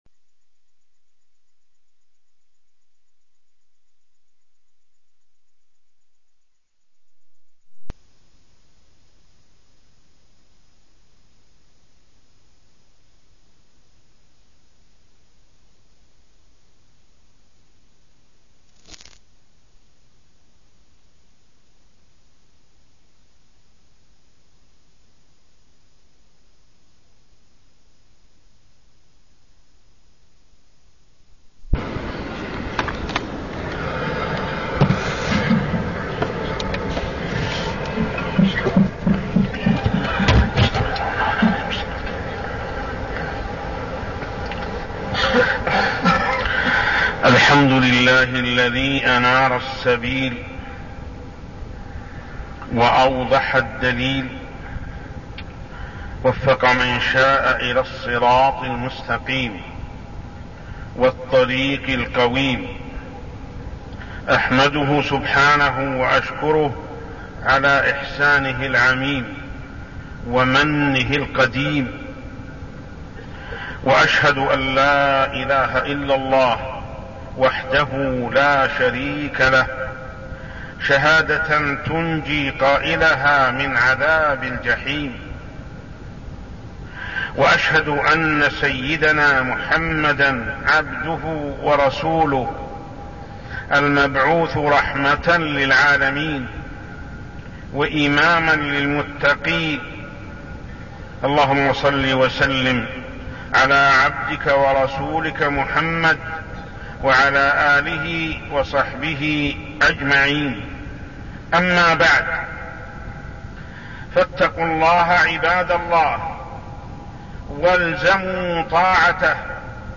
تاريخ النشر ٢١ شعبان ١٤١٣ هـ المكان: المسجد الحرام الشيخ: محمد بن عبد الله السبيل محمد بن عبد الله السبيل طاعة ولي الأمر